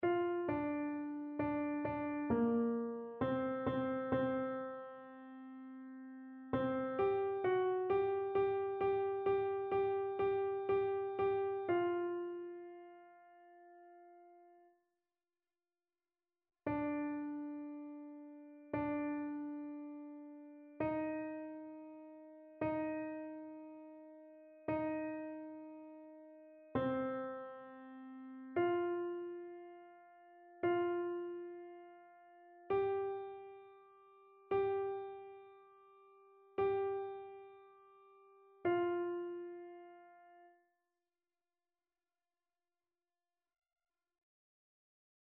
annee-b-temps-ordinaire-6e-dimanche-psaume-31-alto.mp3